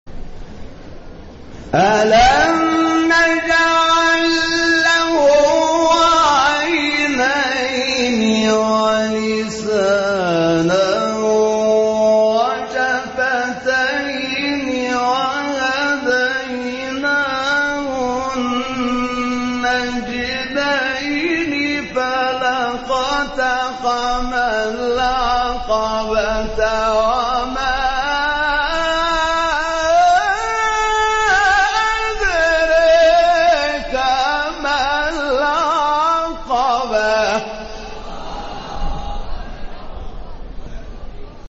گروه فعالیت‌های قرآنی: فرازهای صوتی از قاریان ممتاز کشور ارائه می‌شود.